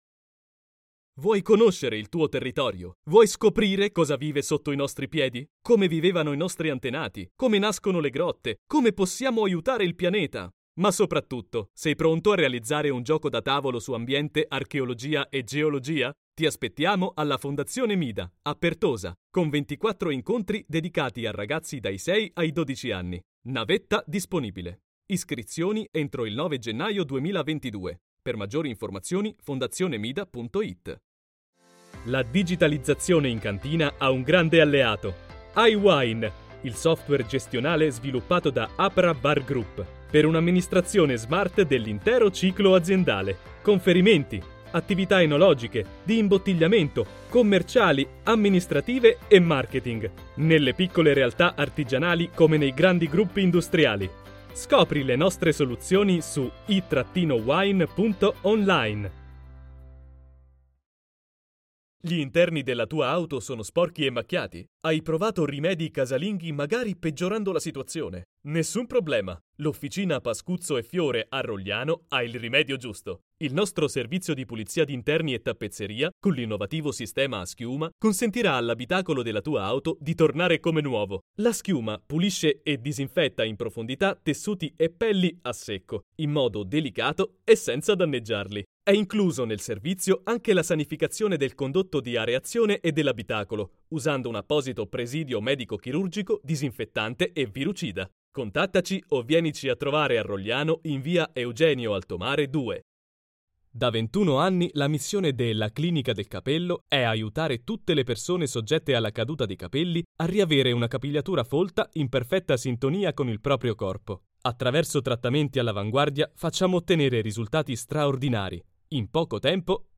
Voce italiana maschile, Voce giovane, Italian male voice
Kein Dialekt
Sprechprobe: Werbung (Muttersprache):